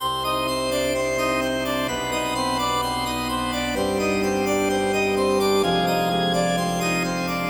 Rok风琴慢速amp；快速旋转音符" c5
描述：c5 16位/ 22050采样率/单声道的摇滚风琴声音
标签： 岩石 器官样品 声音